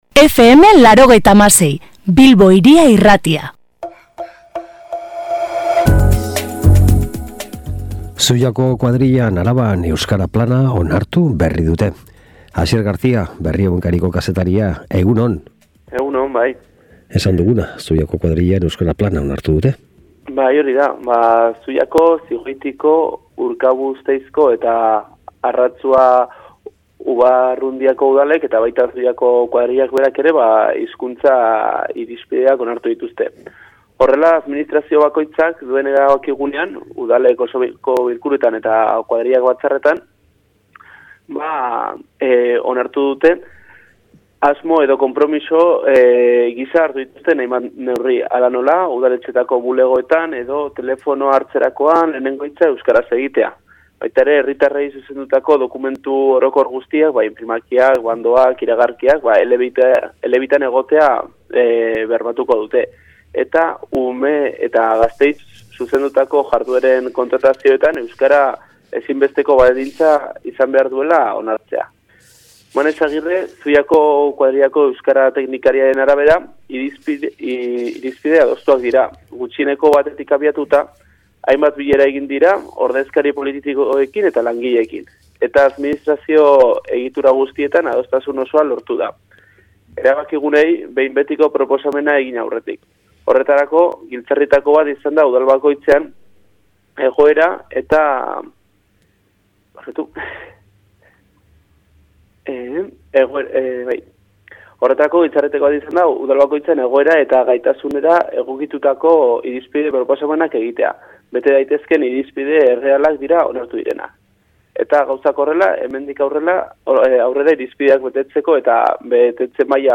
gaurko Arabako kronika